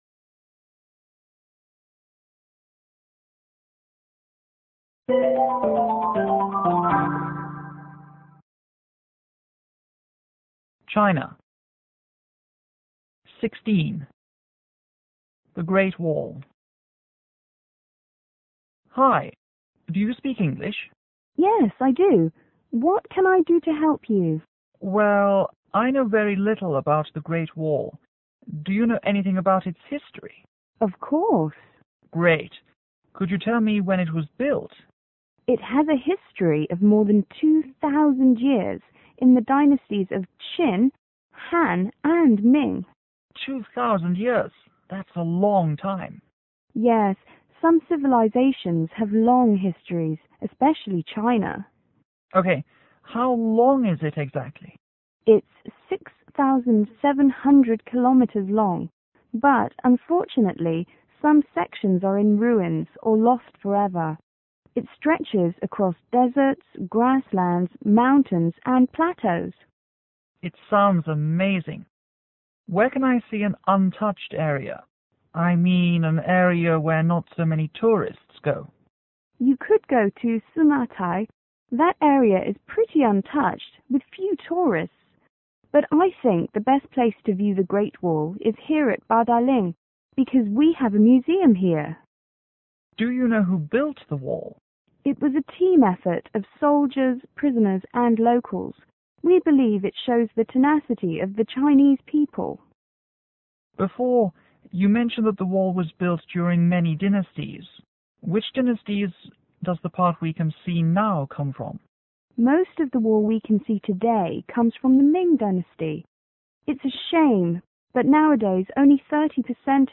T:  Tourist    L: Local